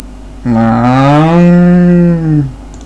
cow.wav